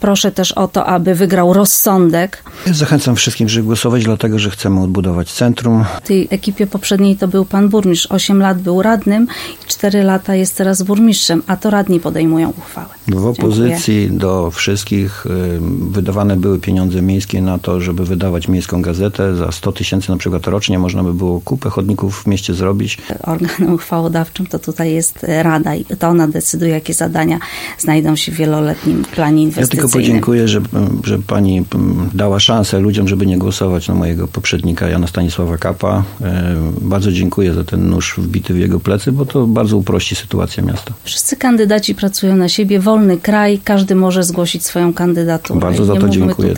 Debata przed II turą
Były pytania o prywatne i zawodowe sukcesy, obietnice i deklaracje wyborcze oraz Sejny same w sobie. Był też konkurs wiedzy o Sejnach czyli swobodna, przedwyborcza dyskusja.